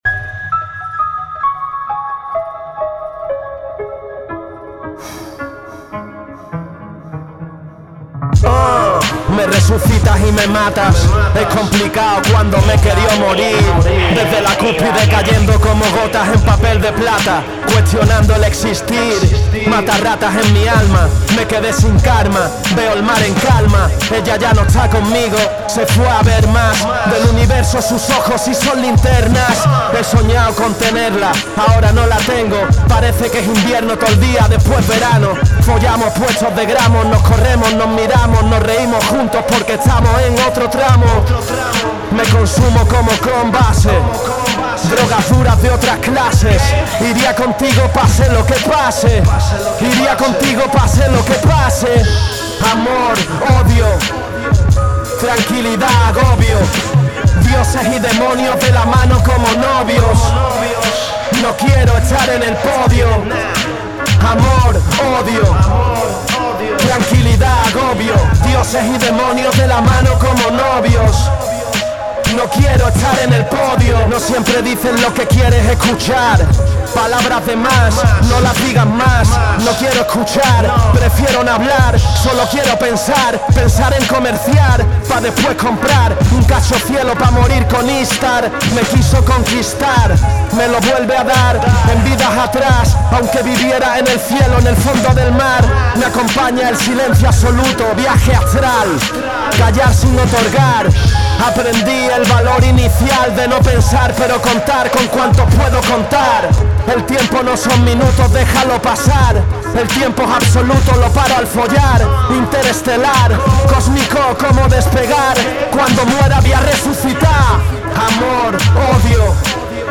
Está grabado, editado, mezclado y masterizado